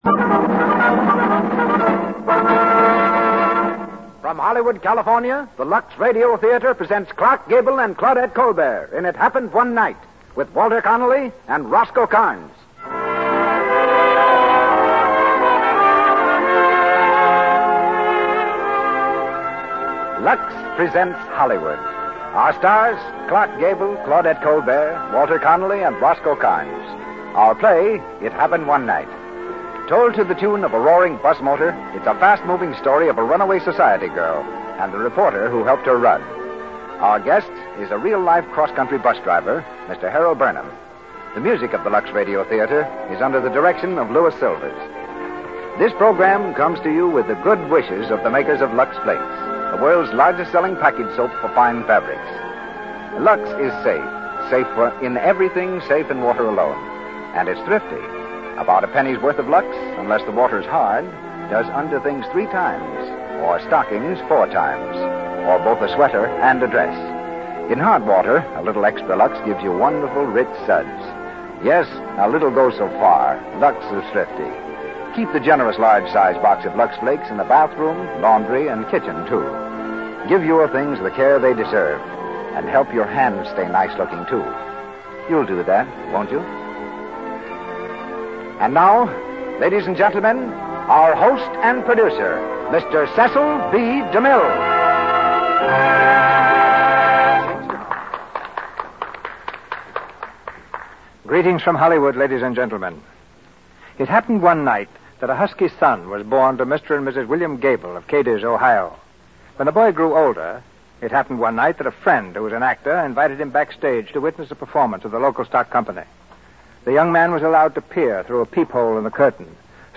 1939-03-19-It-Happened_One-Night-rehearsal.mp3